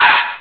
hit_s1.wav